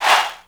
metro overused chant.wav